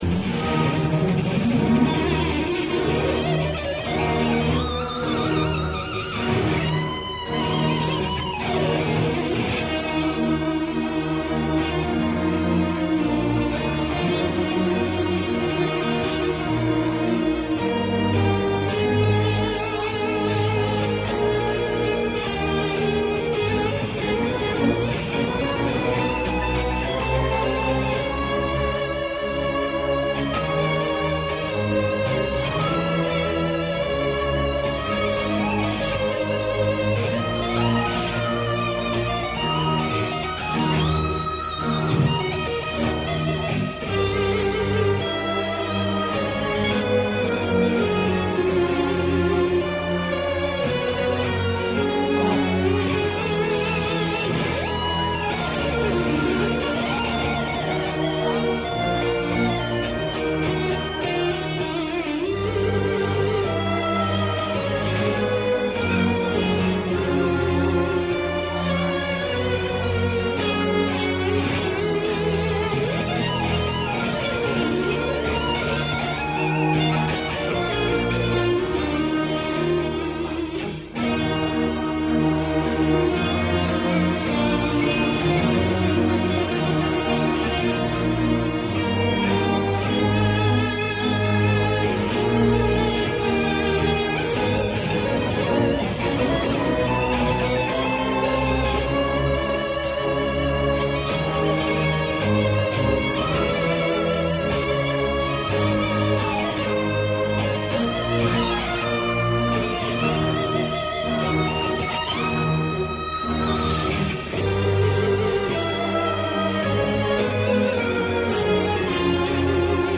In E flat minor Op. 1